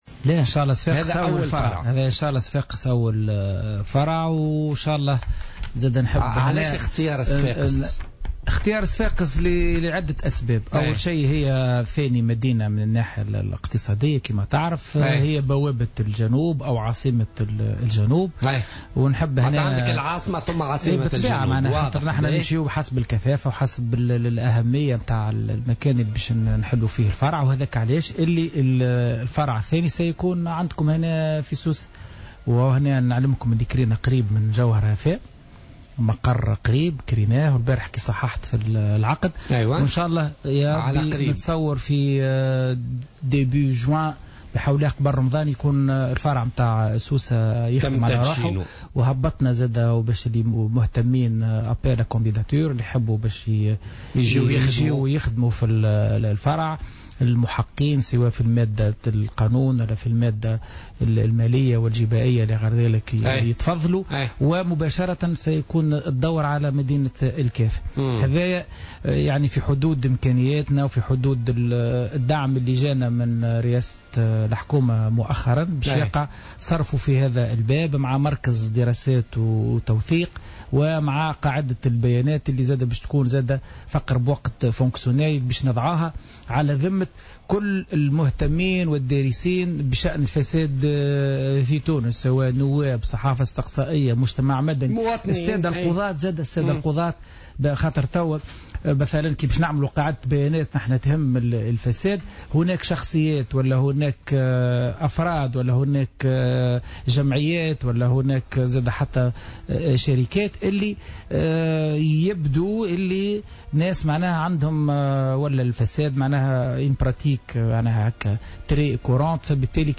وأعلن الطبيب، في حوار مع الجوهرة أف أم خلال حصة "بوليتيكا" اليوم الخميس أن الفرع القادم الذي ستؤسسه الهيئة سيكون في سوسة على أن يتم افتتاحه مطلع شهر جوان القادم، في حين سيكون المكتب الجهوي الثالث في مدينة الكاف، مبينا أن الهيئة تتصرف في الموارد المالية حسب الدعم الذي تقدمه لها الحكومة.